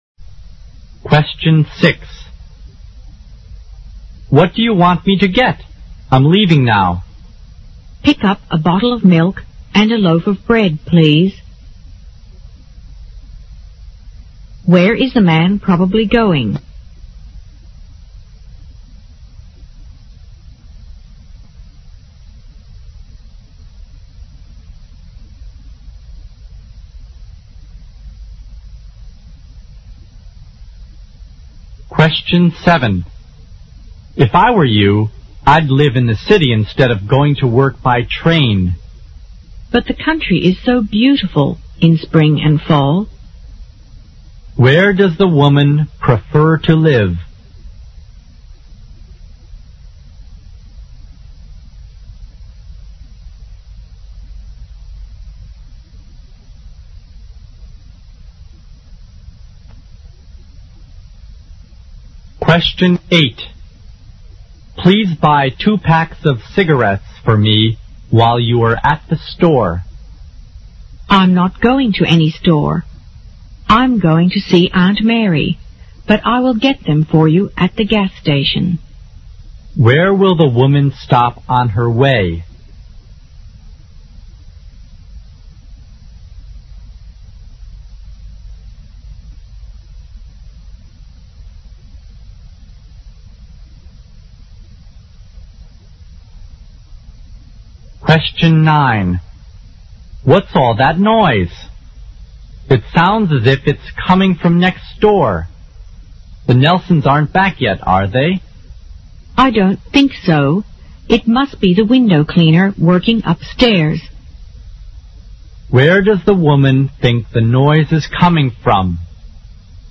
【CET12-24备战】四级听力 第七期 Short Conversation 听力文件下载—在线英语听力室